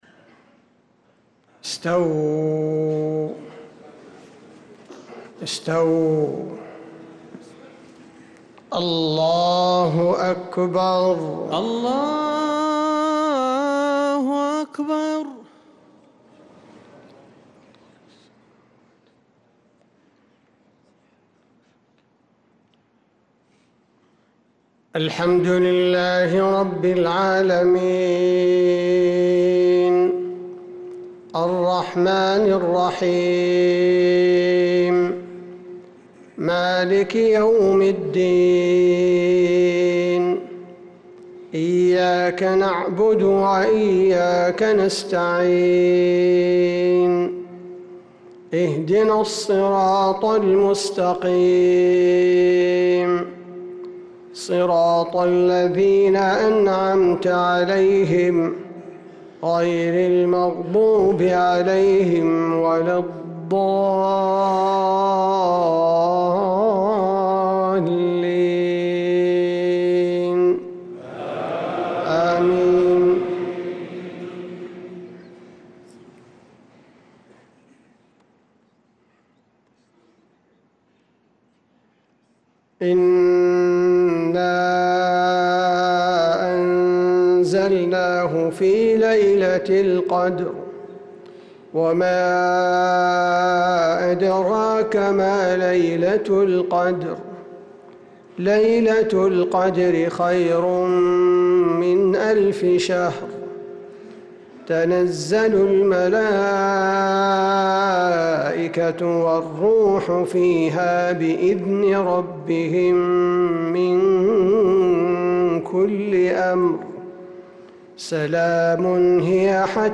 صلاة المغرب للقارئ عبدالباري الثبيتي 14 رجب 1446 هـ
تِلَاوَات الْحَرَمَيْن .